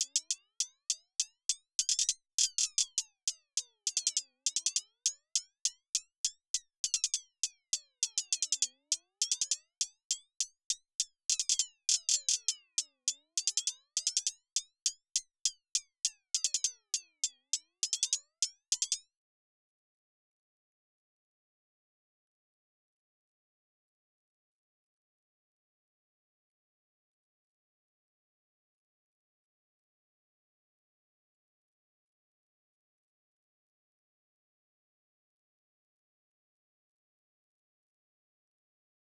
Closed Hats
Boomin-Beat-Starter-6_Hi Hat.wav